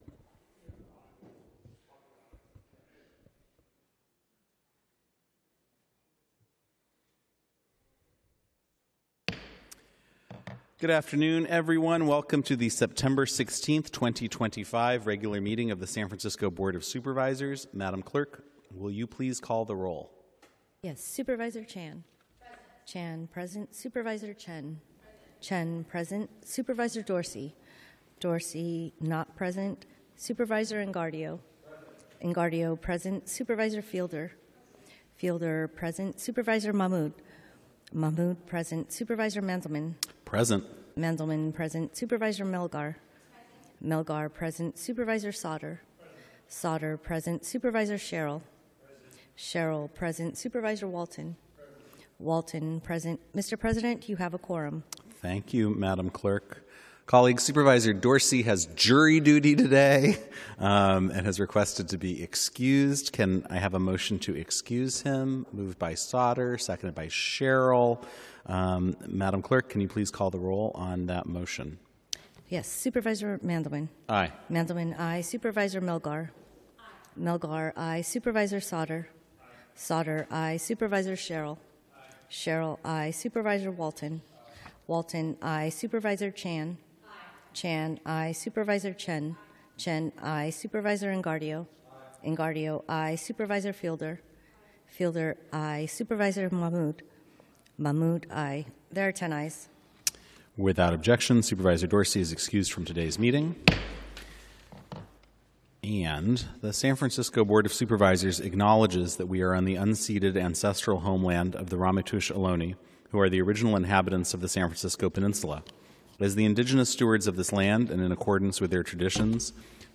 BOS Board of Supervisors - Regular Meeting - Sep 16, 2025